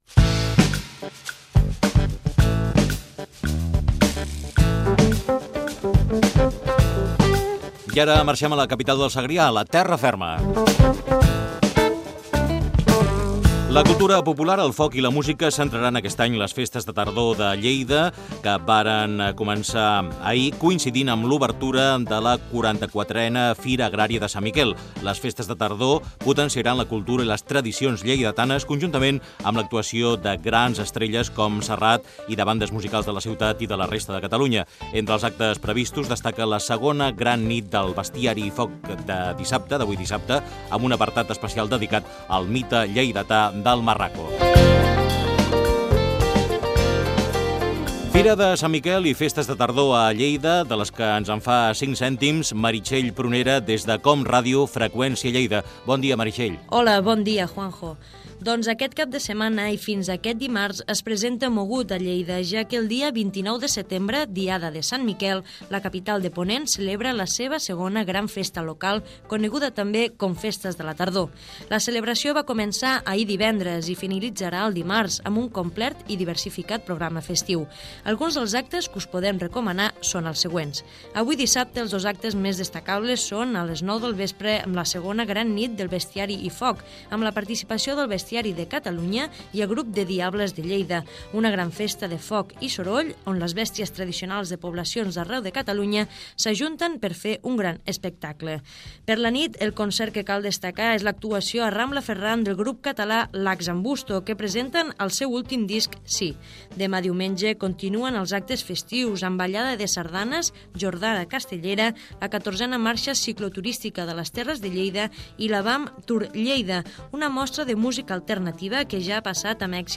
Crònica sobre la fira de Sant Miquel i Fira de tardor de Lleida
Fragment extret de l'arxiu sonor de COM Ràdio.